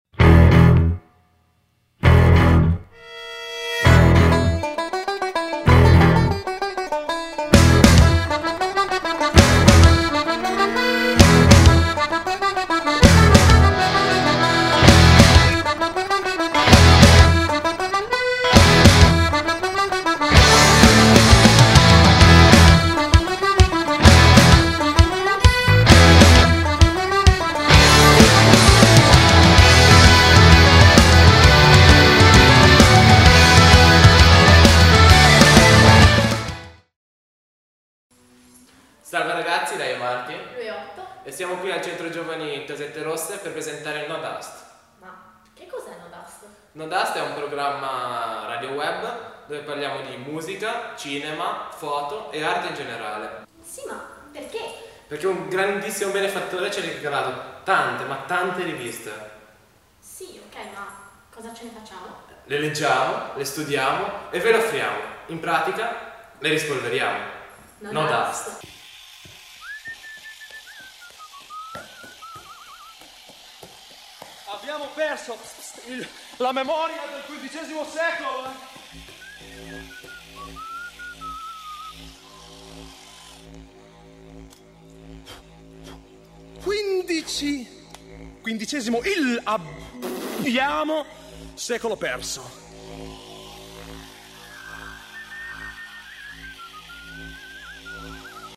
Sigla